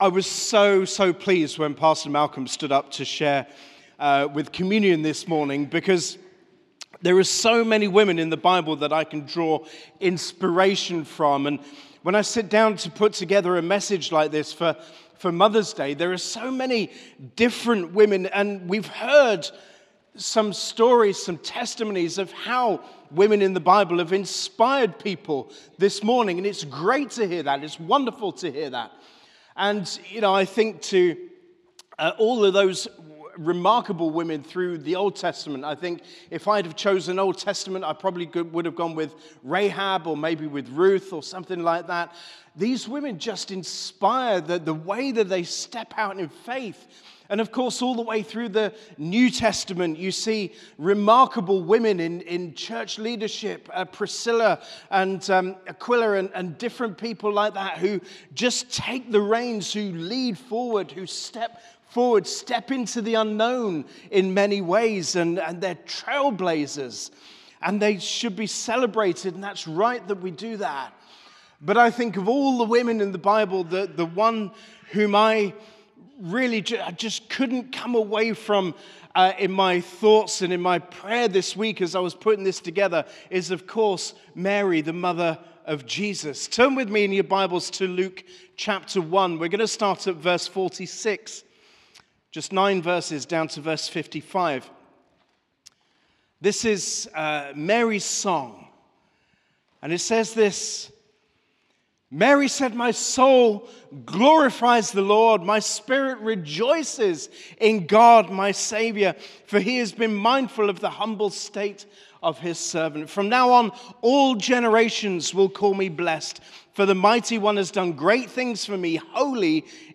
Sermon - Mother's Day